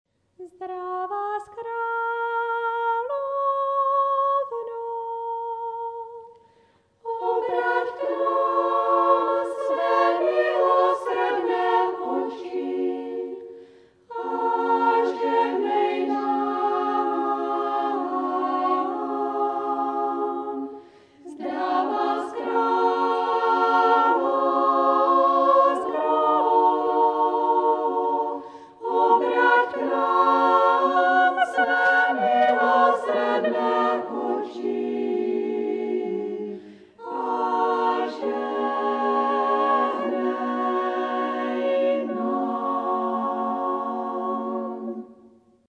Sbor sester boromejek